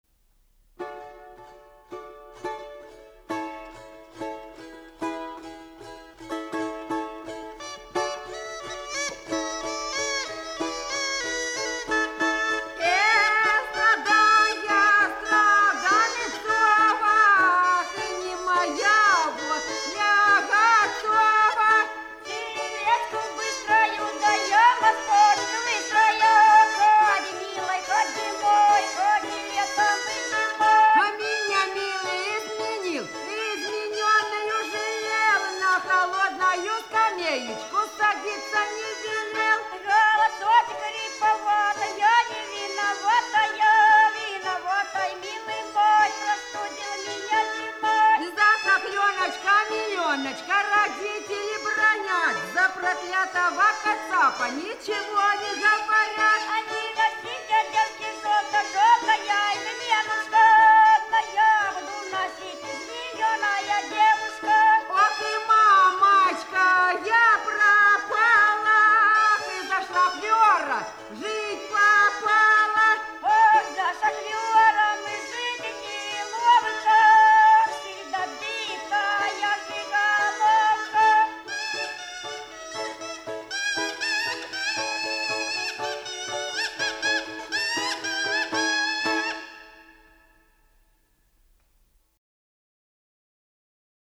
Голоса уходящего века (Курское село Илёк) Страдания (балалайки, рожок, пение)
30_Страдания.mp3